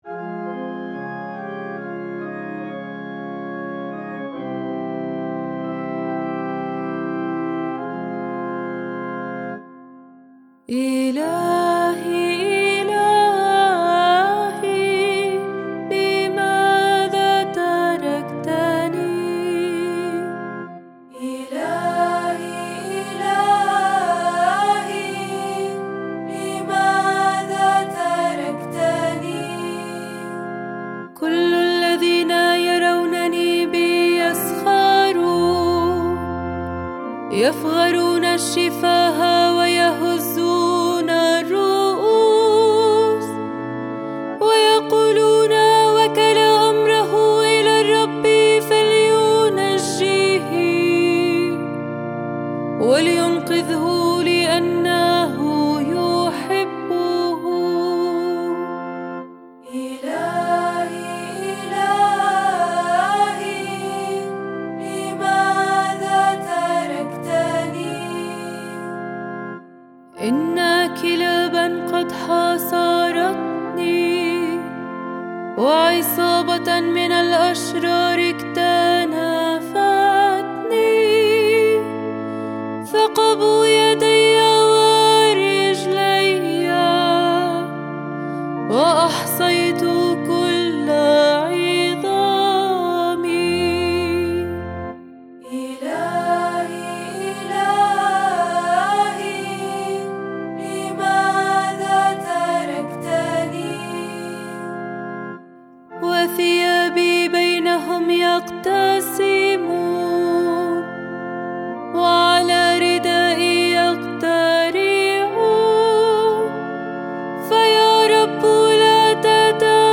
مزمور الردّة لأحد الشعانين (اللحن الثاني) (ك. الأسبوع المقدس-ص 59)